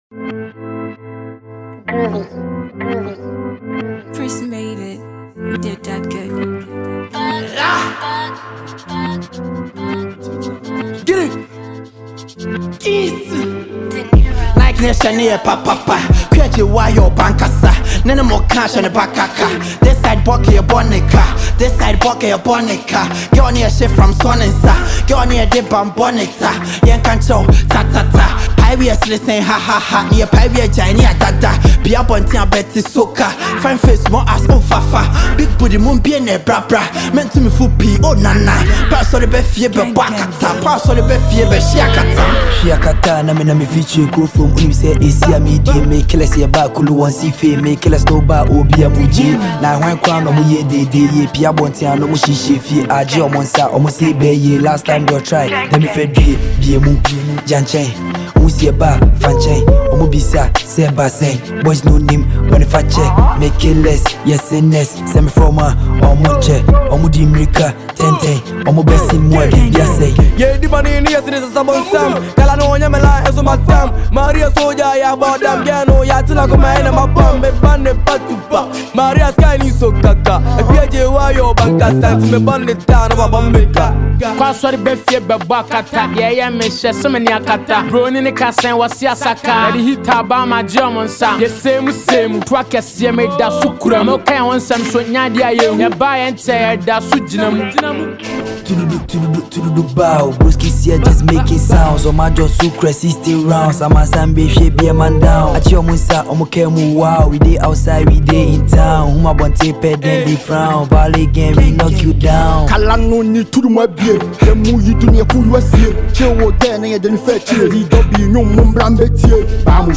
a Ghanaian asakaa musician